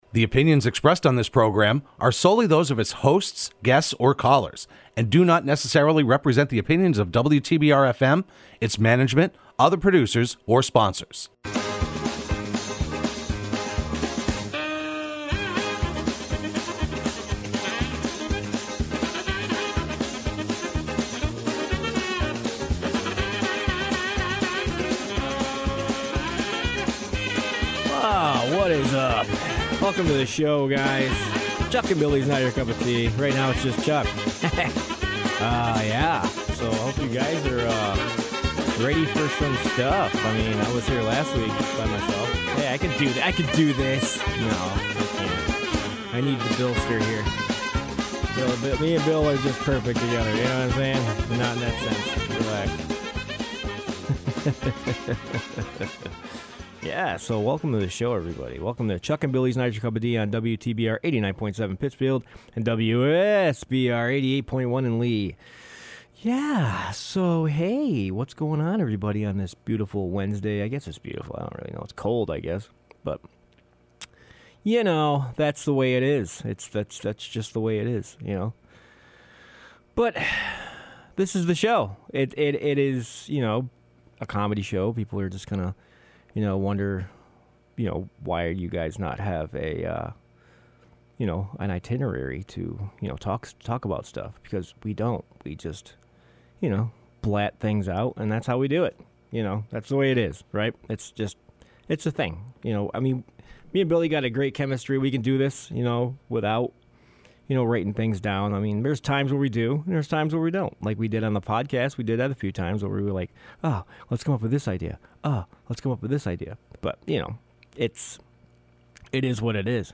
Broadcast live every Wednesday afternoon at 3:30pm on WTBR.